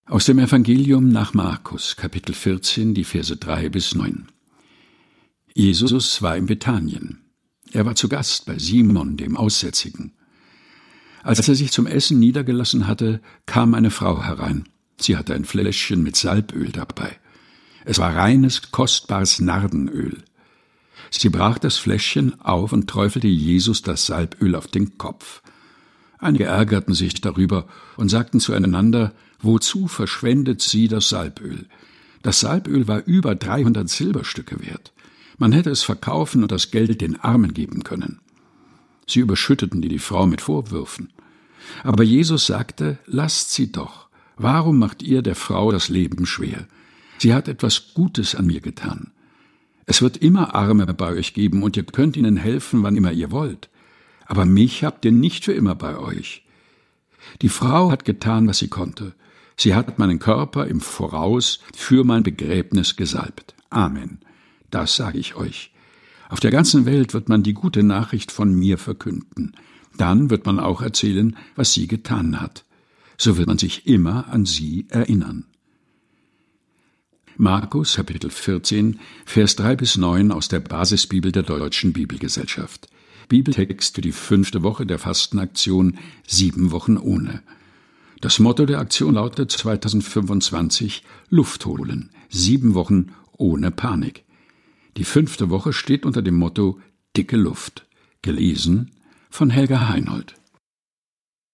Texte zum Mutmachen und Nachdenken - vorgelesen
und liest sie in seinem eigens zwischen Bücherregalen eingerichteten, improvisierten Studio ein.